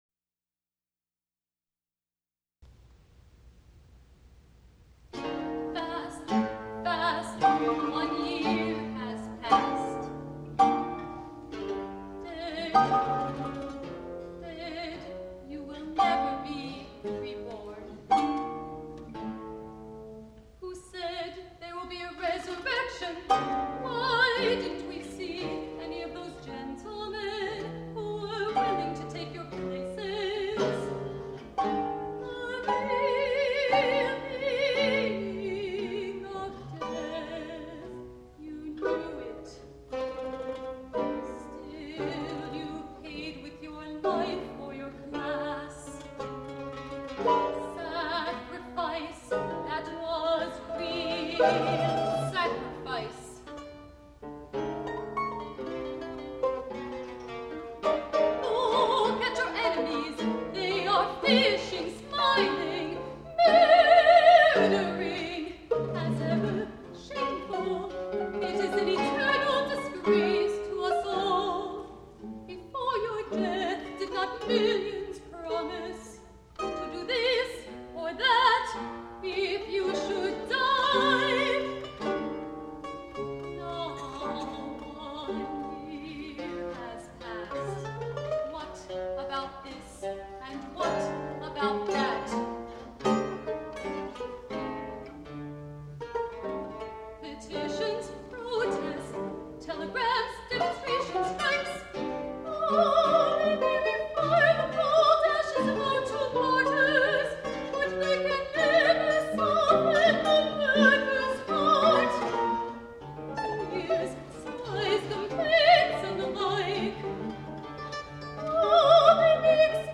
Santa Cruz performance, 1/26/86
(dub from cassette, 2/05)
troubadour harp in CL
mandocello
mandola
soprano